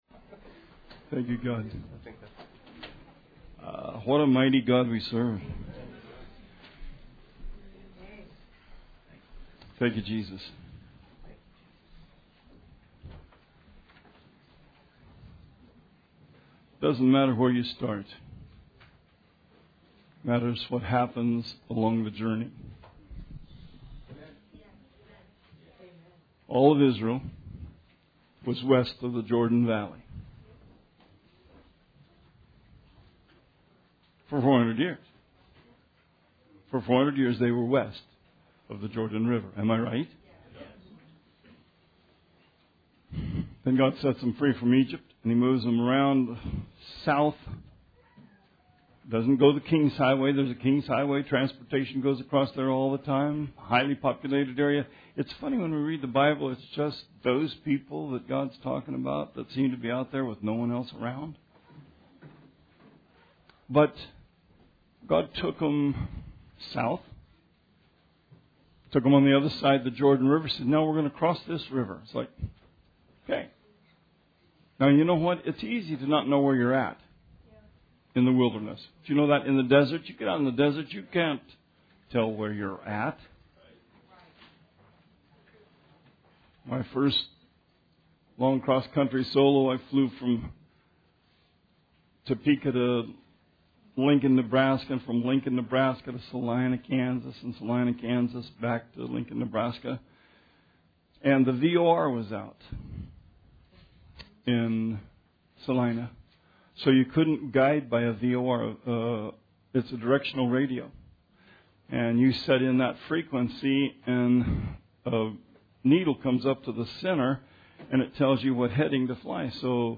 Sermon 8/12/18